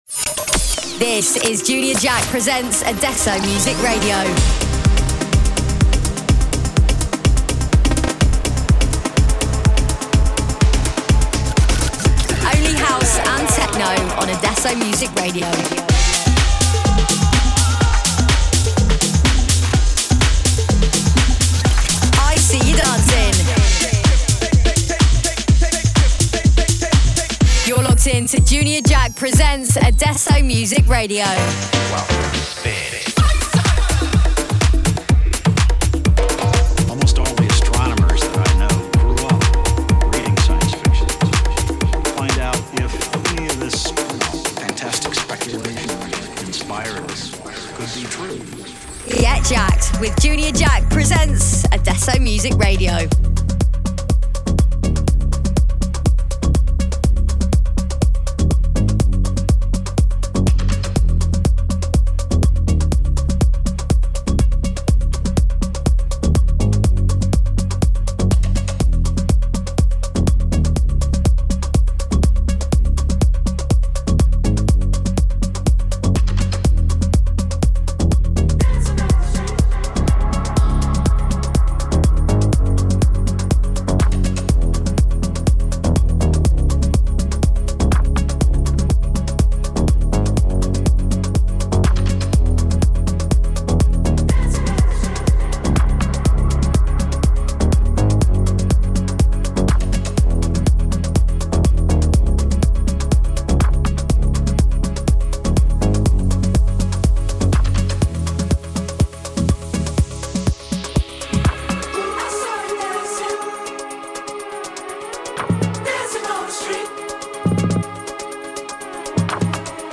Also find other EDM
The weekly radio show